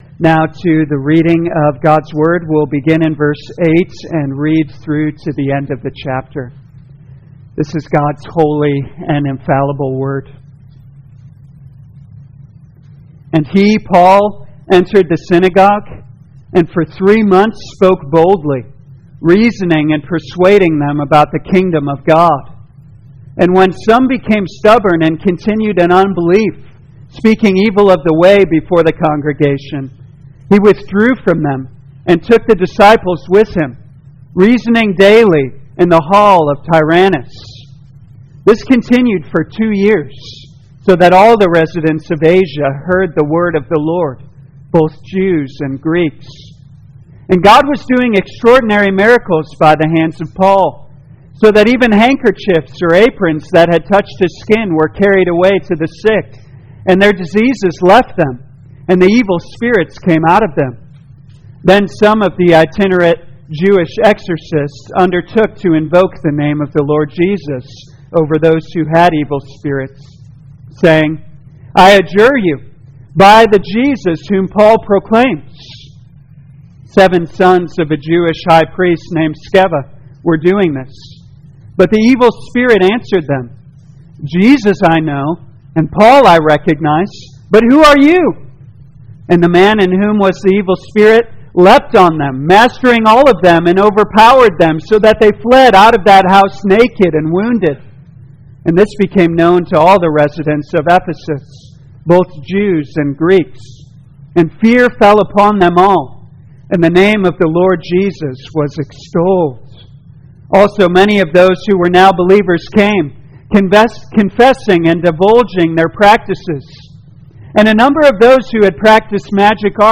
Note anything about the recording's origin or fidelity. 2022 Acts Morning Service Download